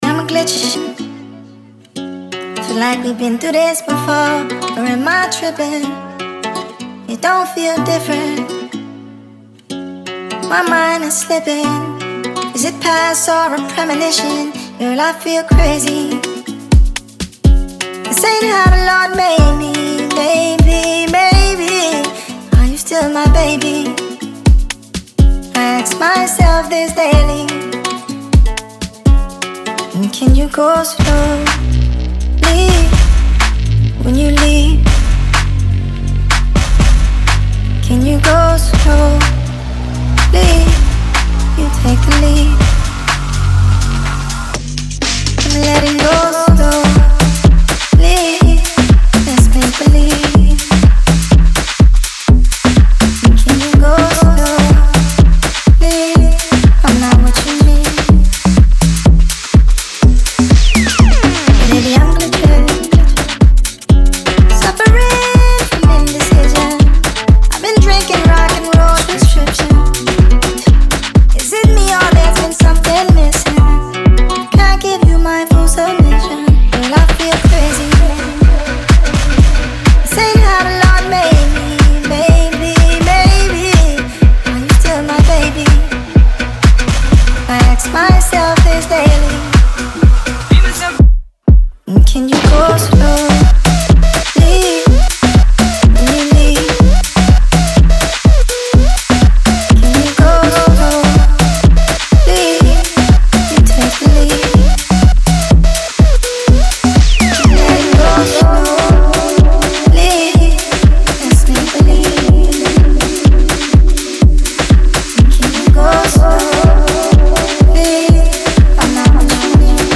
• Жанр: Pop, Electronic